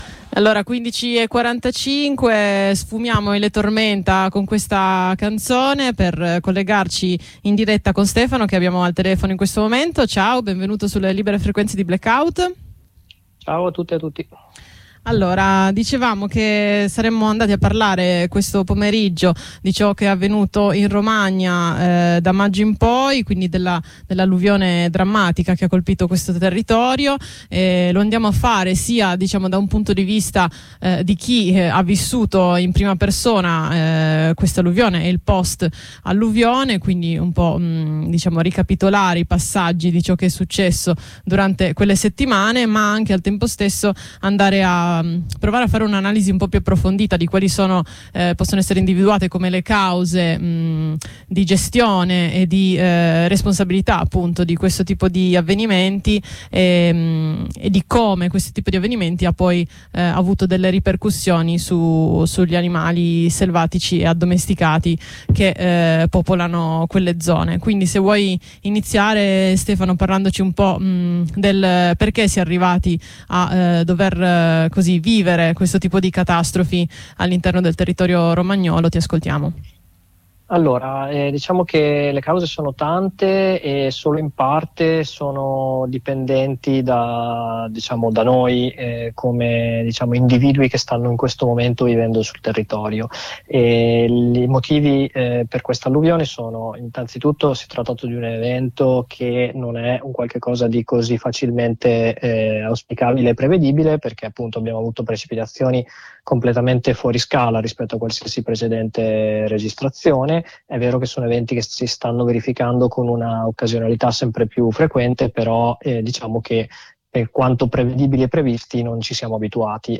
Le immagini che riguardano gli allevamenti allagati, con gli animali annegati o con l’acqua alla gola, dimostrano (se fosse ancora necessario) come anche negli episodi emergenziali queste strutture sono trappole mortali senza scampo. Con un residente della Romagna abbiamo raccontato la storia della gestione di questo territorio e l’effetto che l’alluvione ha avuto sugli animali non solo allevati ma anche selvatici; una testimonianza che ci dovrebbe portare a riflettere approfonditamente sul modo in cui viviamo e modifichiamo i territori, sul nostro rapporto con gli altri animali e sui fenomeni climatici estremi come quelli che continuano e continueranno a ripetersi nei prossimi anni.